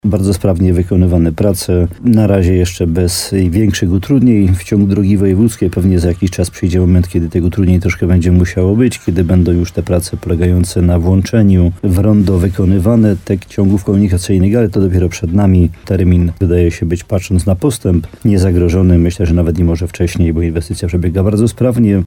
Jak mówi wójt gminy Łącko Jan Dziedzina, inwestycja wchodzi dopiero w etap, na którym kierowcy będą musieli liczyć się z utrudnieniami.